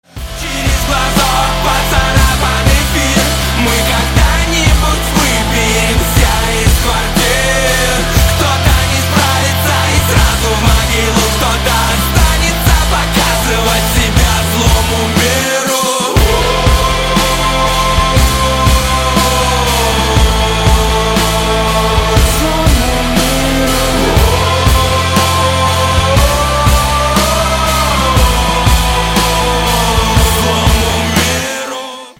Громкие Рингтоны С Басами » # Рингтоны Альтернатива
Рок Металл Рингтоны